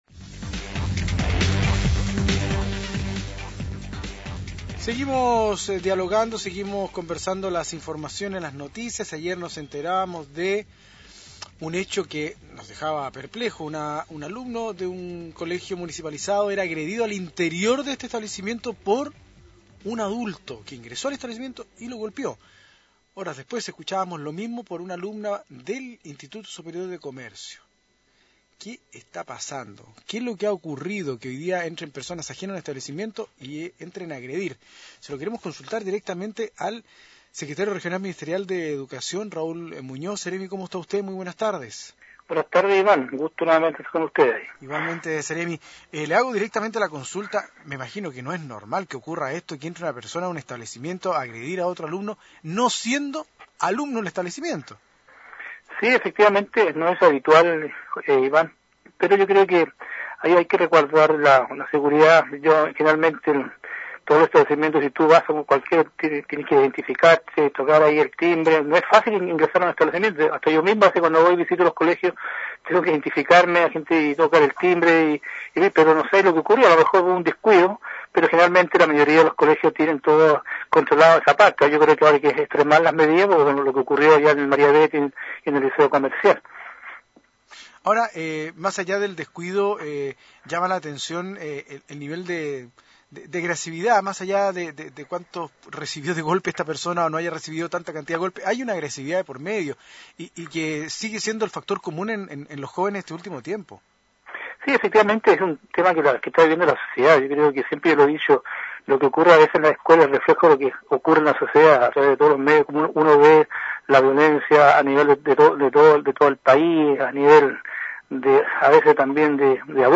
Entrevistas de Pingüino Radio
Raúl Muñoz, seremi de Educación